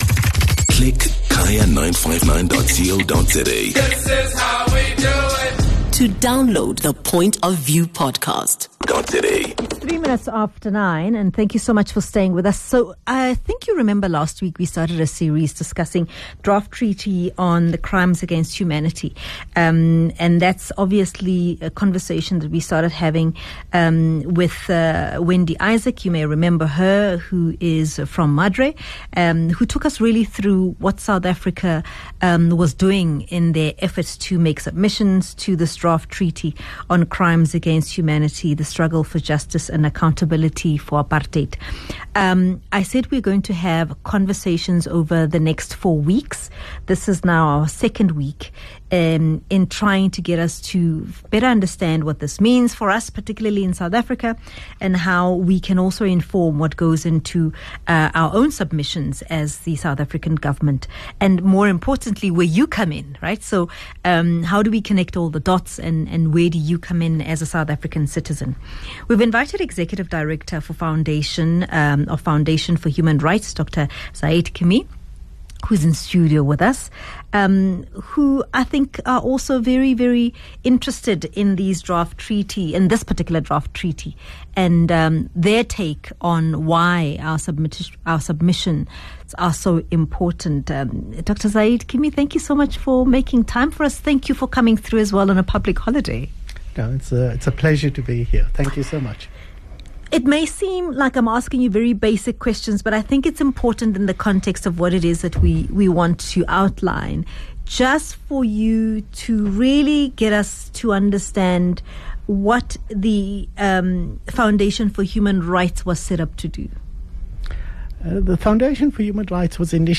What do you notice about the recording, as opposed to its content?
In this week’s part 2 of the UN Crimes Against Humanity Draft Treaty we are joined in studio